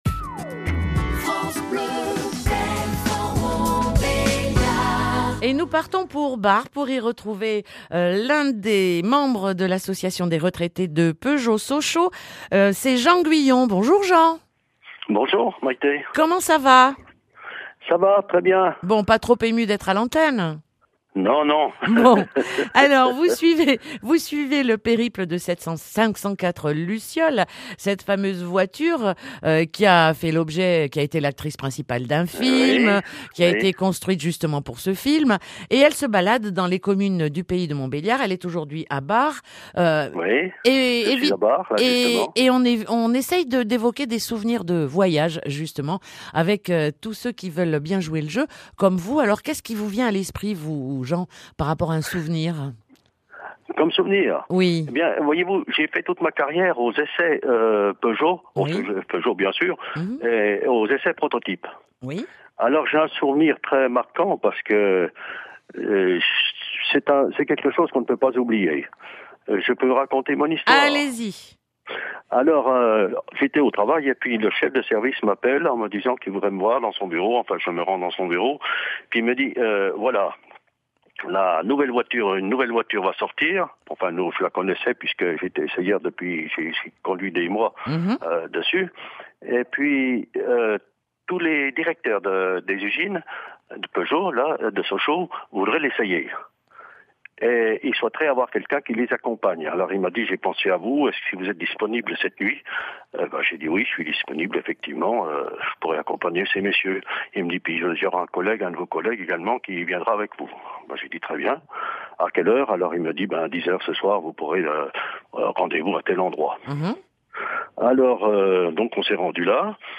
Des retraités du plus grand site industriel de France, PSA Sochaux, qui fête ses 100 ans en 2012, ont fait la médiation de l’oeuvre dans les 29 communes pour y collecter des histoires de voyages, diffusées chaque jour sur France Bleu Belfort-Montbéliard.